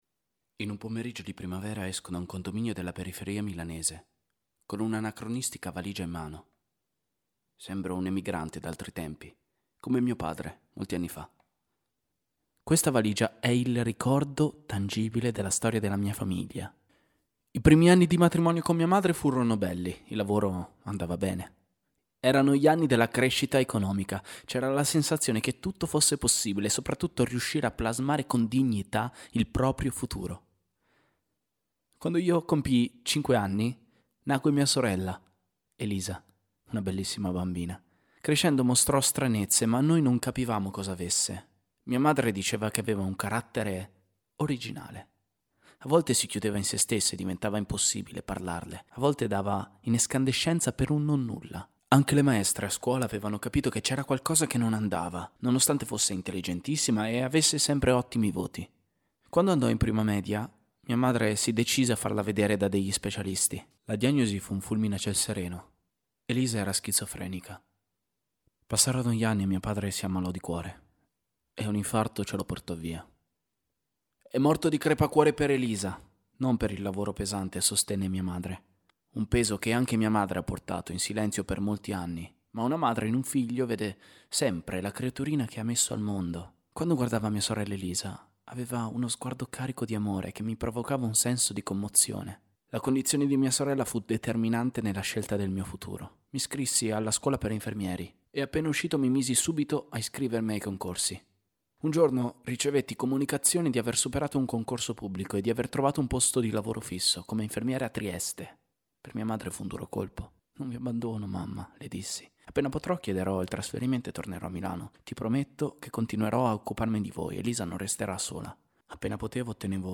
attore di teatro